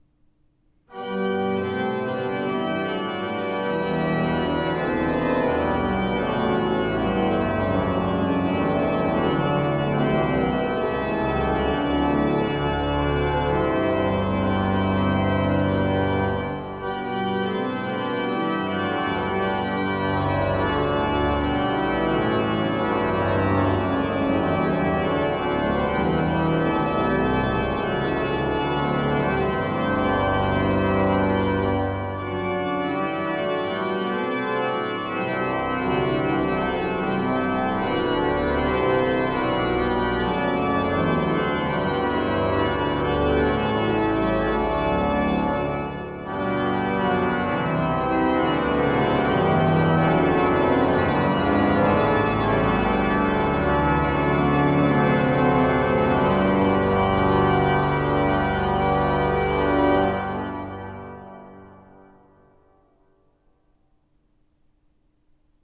Orgel
Choralvorspiel von Max Reger (1873-1916)
Sie verfügt heute über 47 Register, 2915 Pfeifen verteilt auf vier Manuale und Pedal.